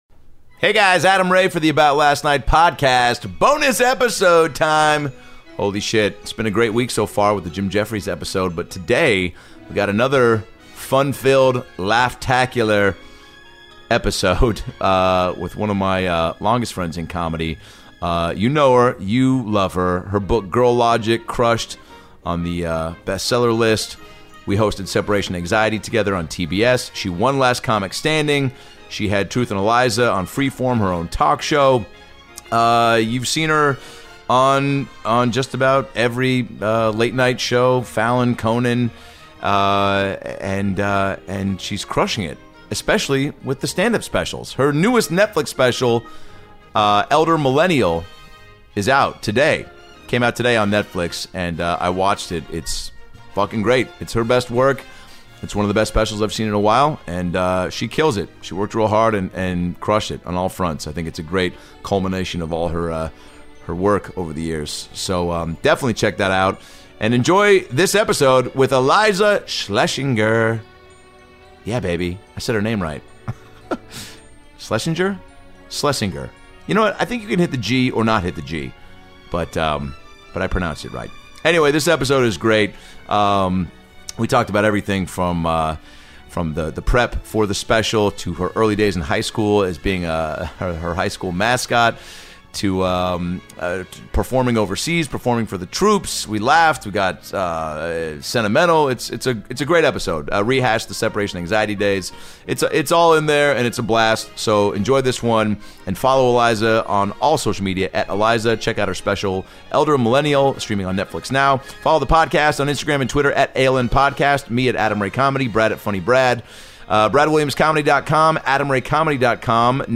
The hilarious Iliza Shlesinger returns to the ALN podcast for a fun one-on-one chat with her buddy Adam Ray. The two discuss everything from her recent marriage, performing overseas, filming her new NETFLIX special “Elder Millennial” on an aircraft carrier, and reminiscing about their hosting days on the TBS game show SEPARATION ANXIETY.
Laughs, heart, and more laughs.